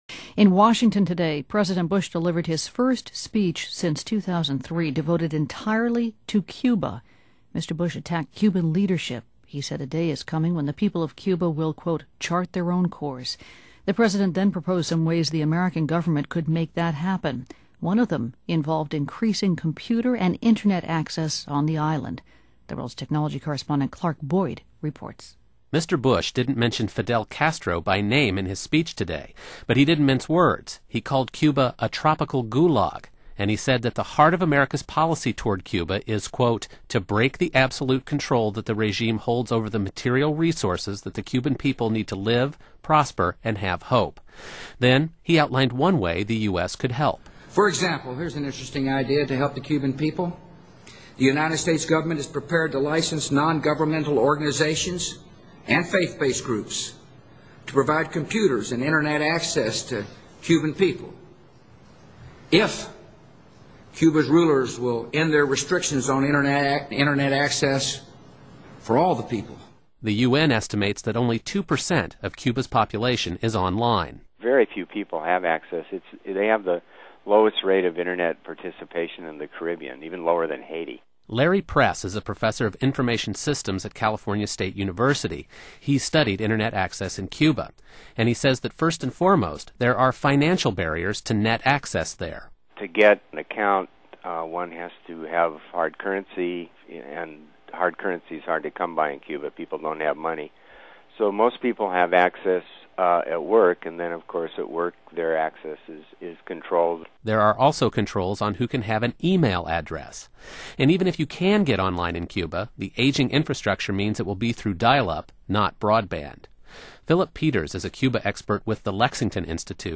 short interview with a few sound bites from me and two others. Note that I compare the rate of Internet usage in Cuba with that of Haiti, and I speak in complete sentences.